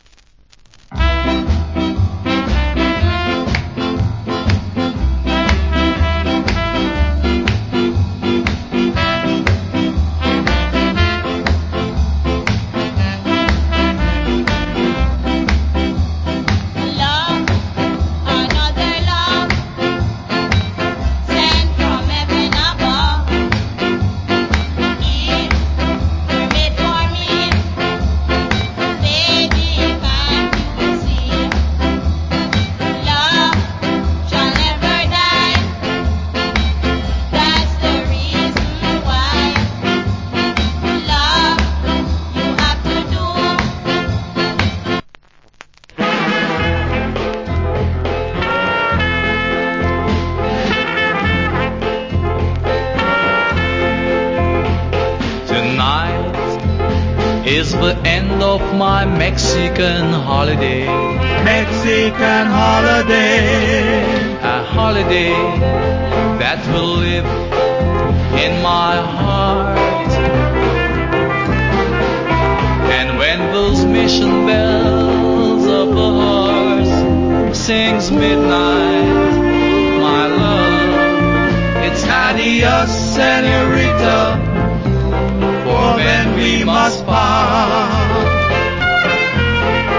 Nice Female Ska Vocal.